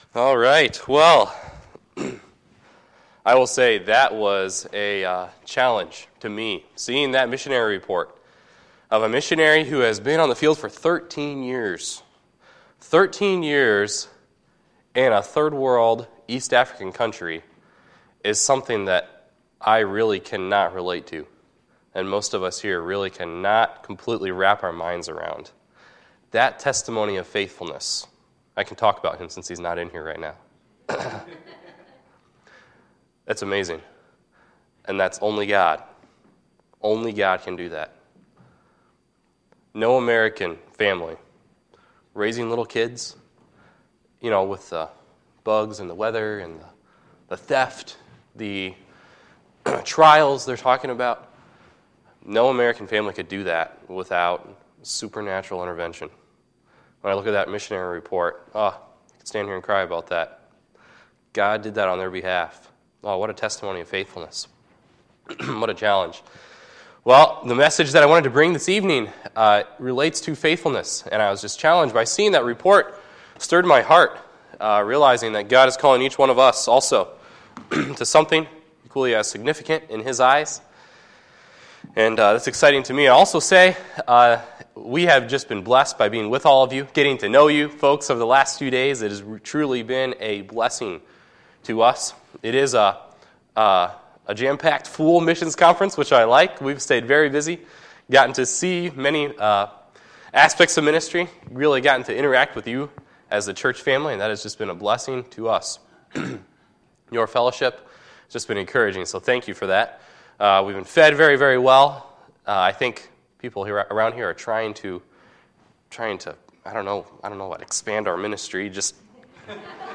Tuesday, September 26, 2017 – Missions Conference Tuesday PM Service
Sermons